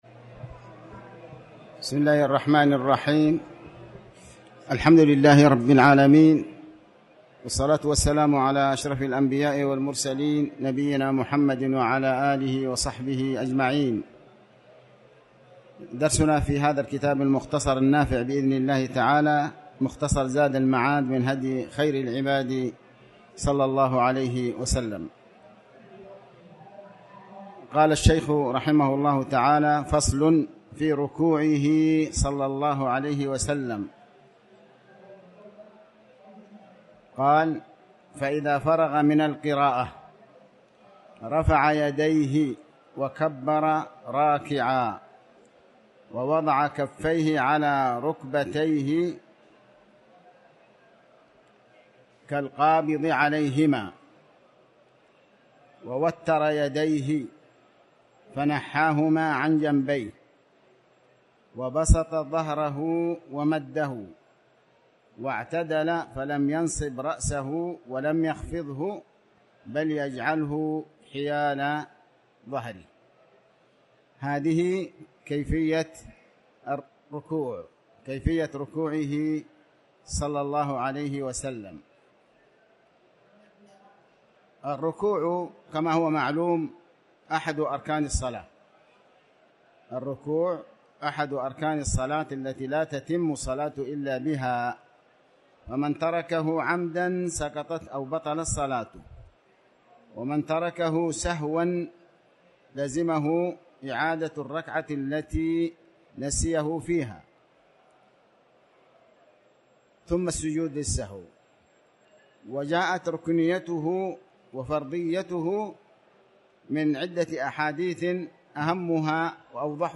تاريخ النشر ١٥ صفر ١٤٤٠ هـ المكان: المسجد الحرام الشيخ: علي بن عباس الحكمي علي بن عباس الحكمي صفة الصلاة